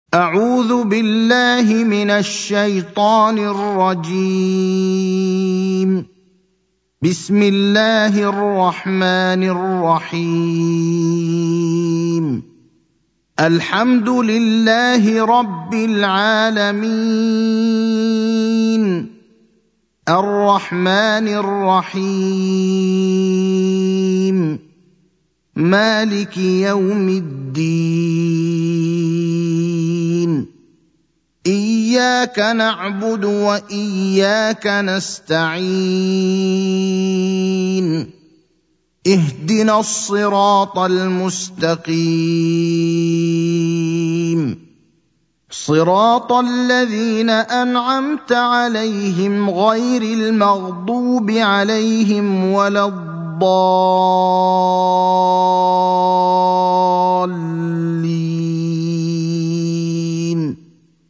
المكان: المسجد النبوي الشيخ: فضيلة الشيخ إبراهيم الأخضر فضيلة الشيخ إبراهيم الأخضر سورة الفاتحة